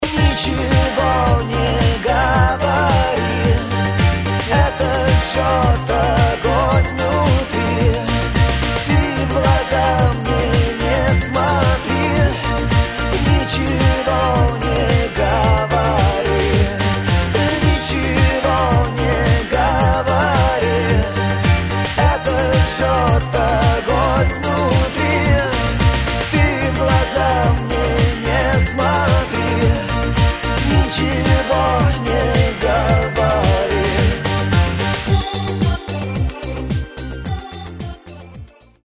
• Качество: 128, Stereo
80-ые